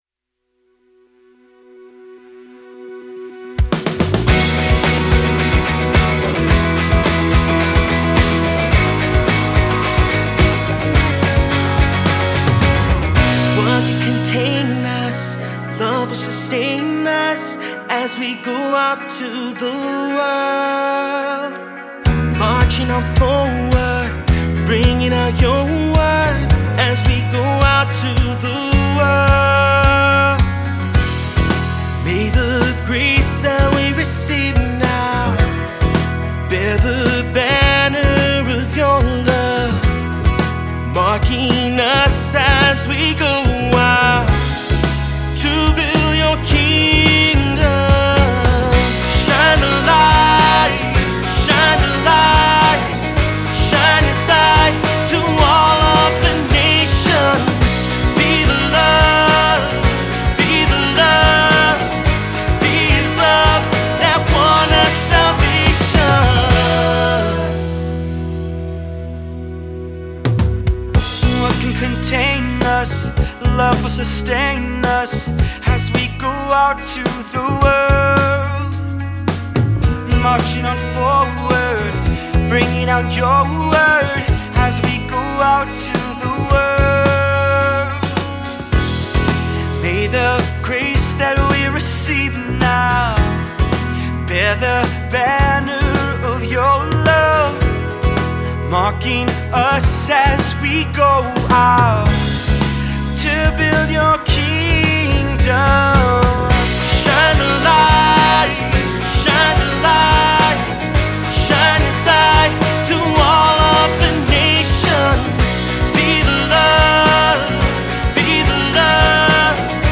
Voicing: SATB; Cantor; Solo; Assembly